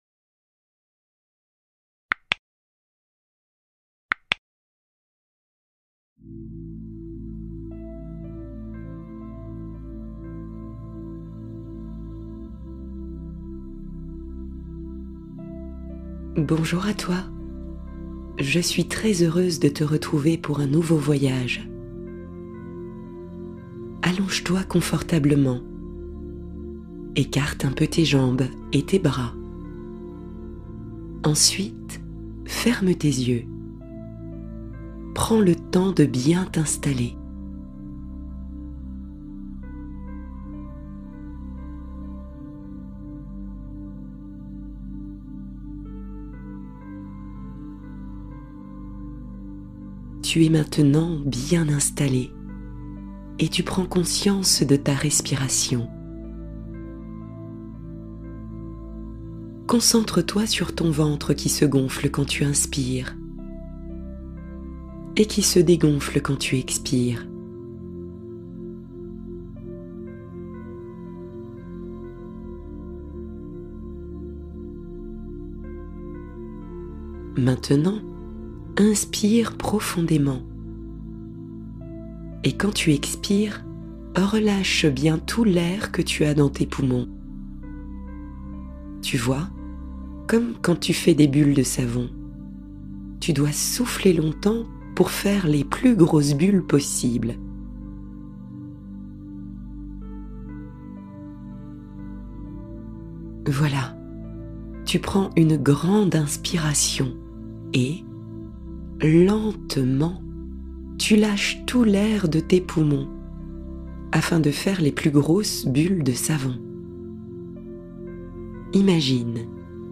Apaiser le corps émotionnel : relaxation guidée du soir